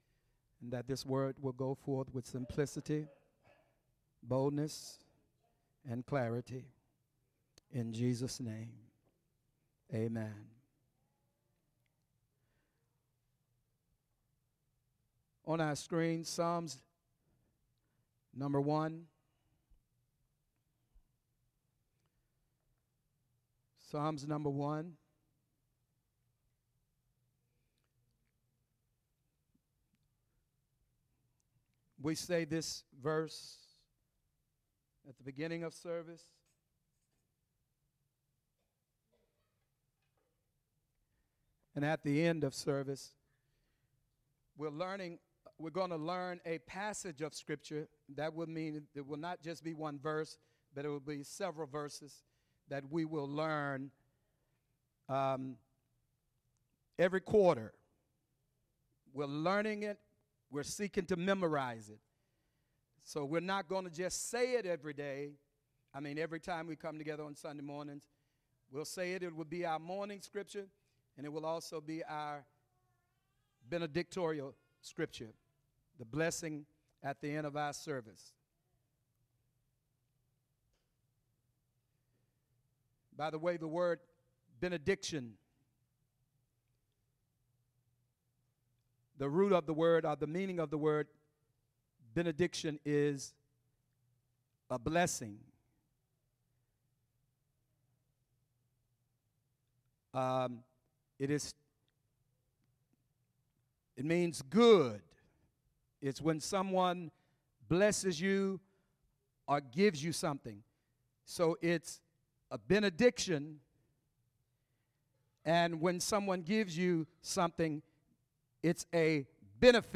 Sermons - Bountiful Blessings World Fellowship